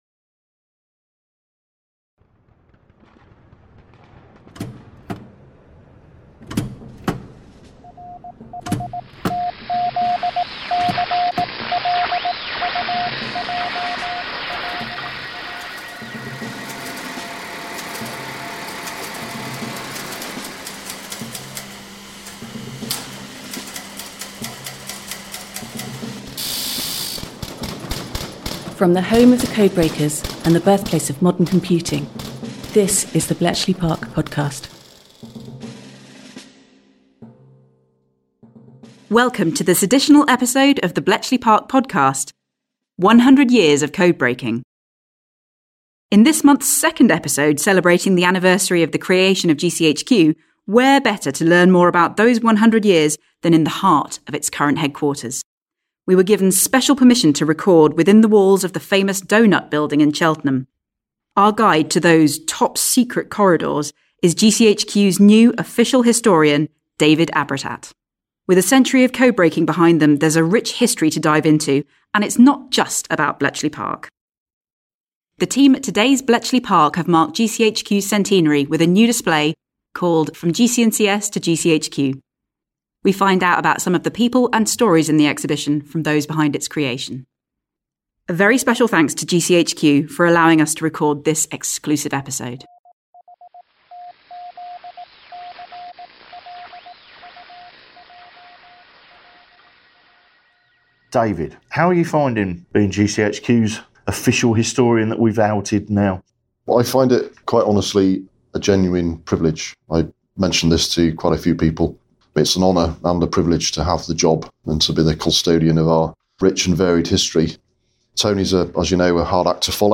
Facebook Twitter Headliner Embed Embed Code See more options November 2019 In this month’s 2nd episode celebrating the anniversary of the creation of GCHQ, where better to learn more about those 100 years than in the heart of its current Headquarters. We were given special permission to record within the walls of the famous ‘Doughnut’ building in Cheltenham.